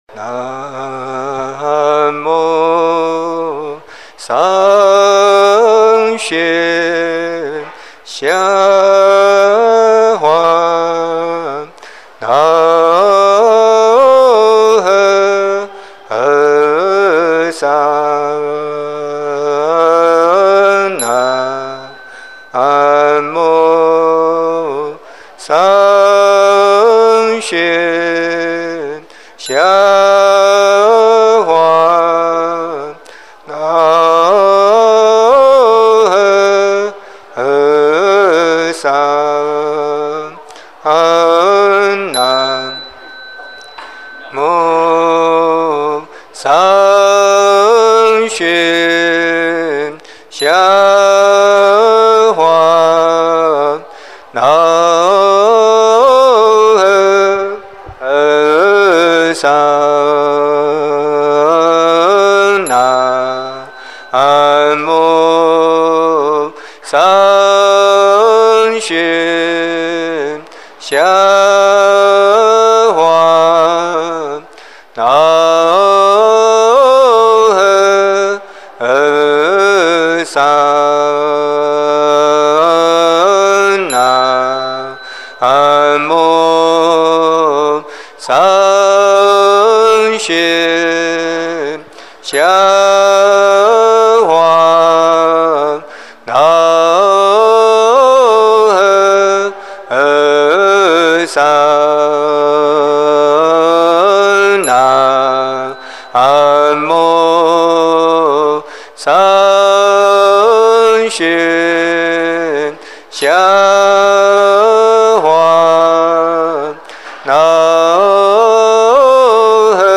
Recitation: